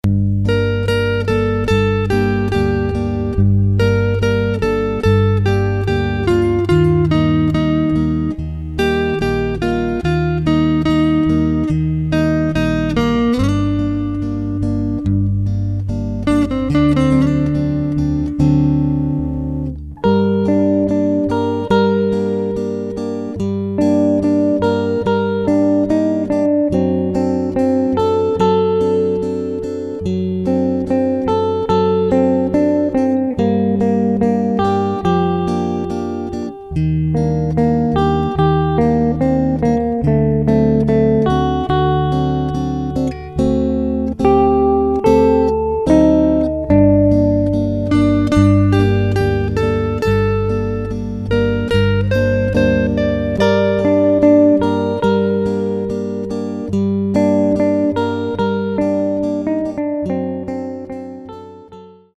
Chitarre e Basso